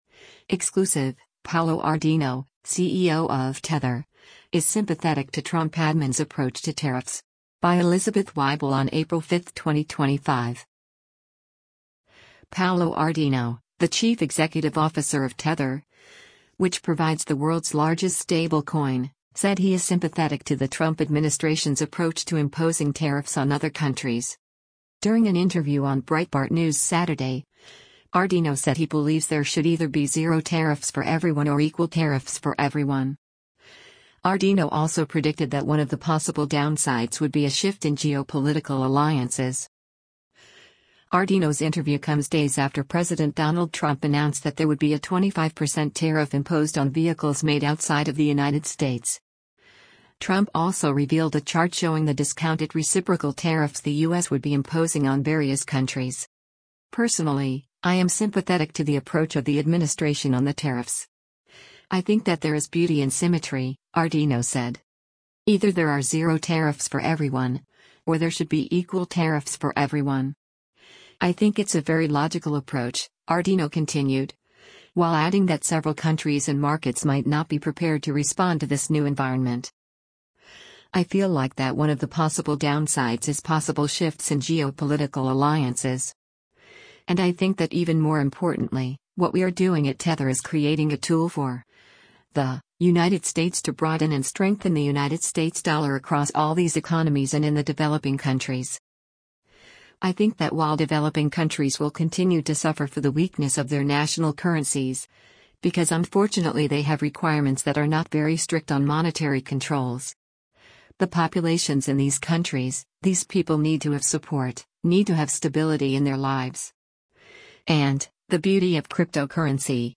During an interview on Breitbart News Saturday, Ardoino said he believes there should either be “zero tariffs for everyone” or “equal tariffs for everyone.”